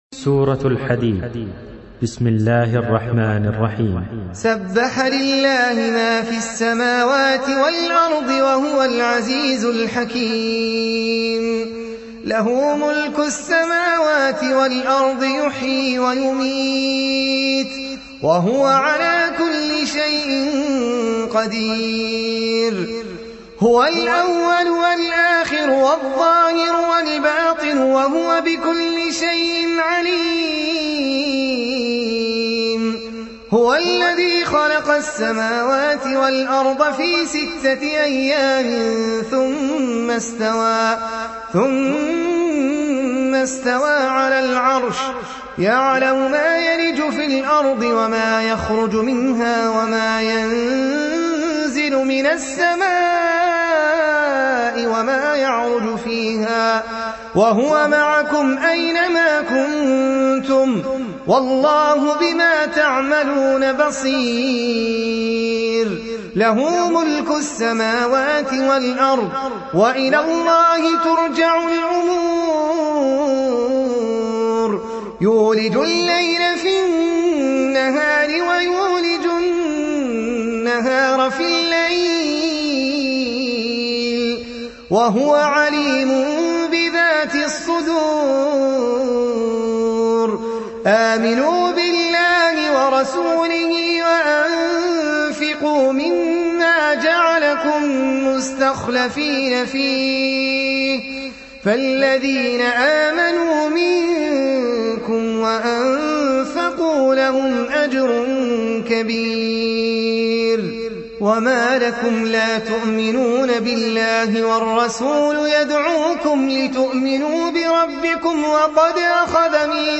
Écoutez et téléchargez le Saint Coran en ligne récité par différents récitateurs.